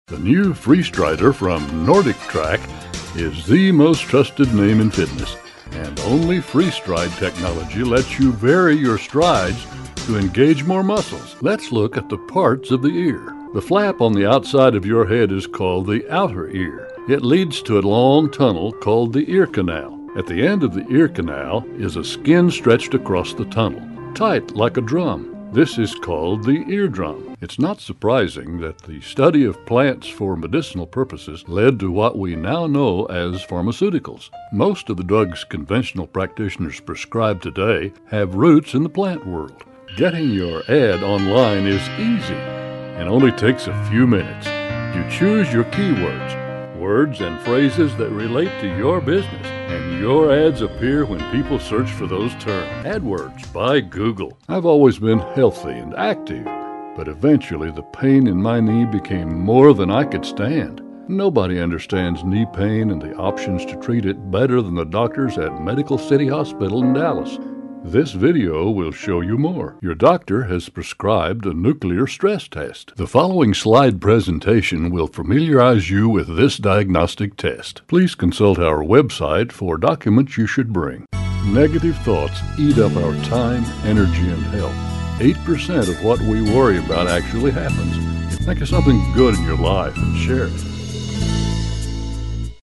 Sprechprobe: Industrie (Muttersprache):
A Bass/Baritone with a hint of the Southwest